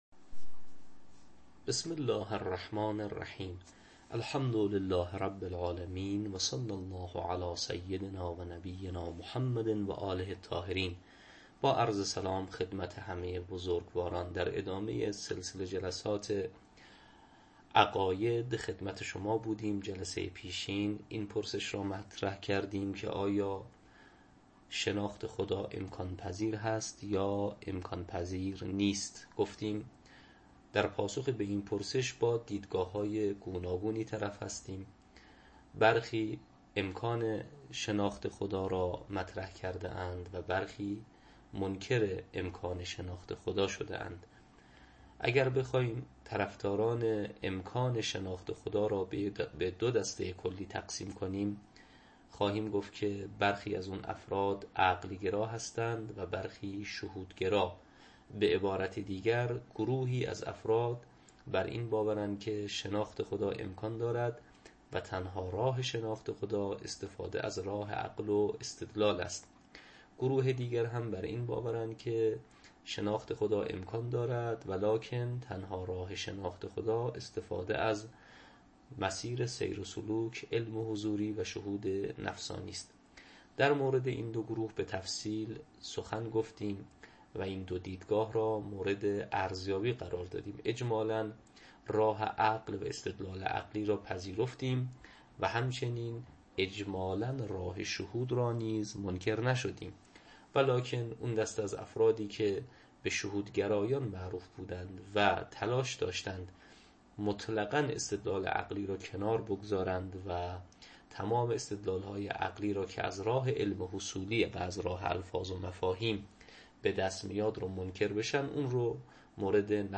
تدریس عقاید استدلالی یک